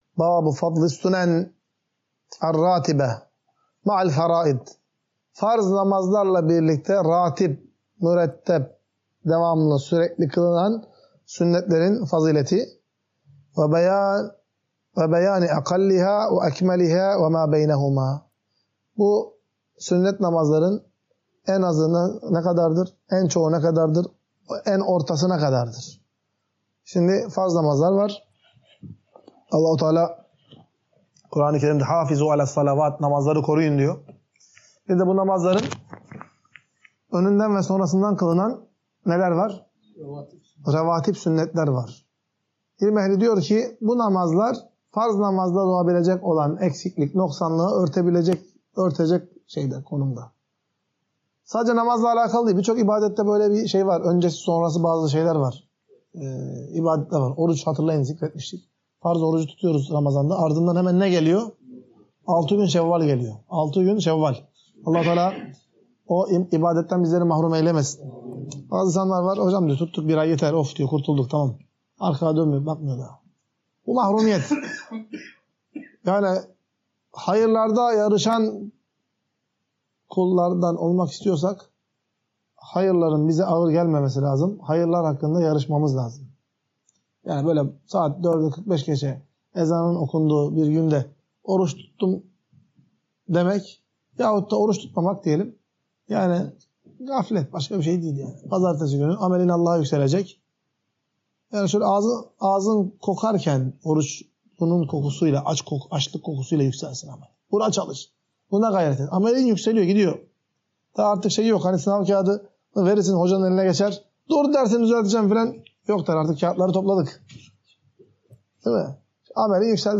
Ders - 16.